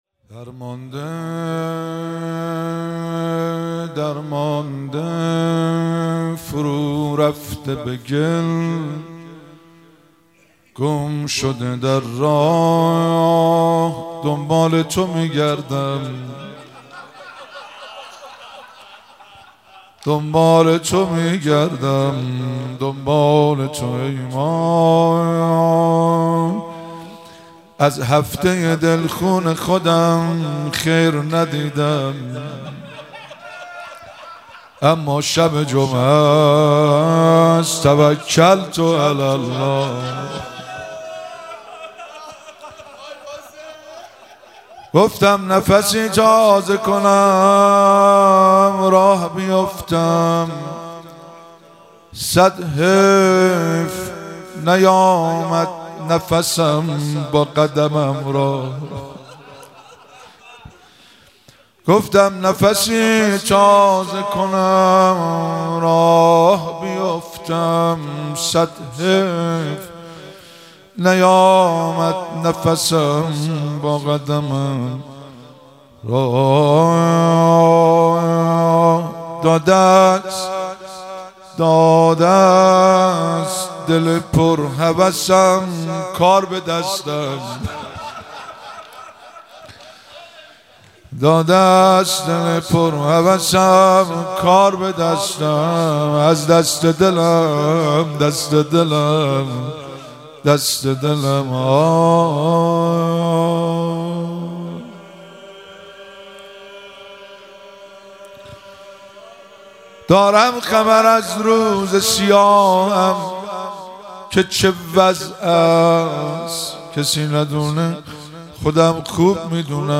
مراسم مناجات خوانی شب دوم ماه رمضان 1444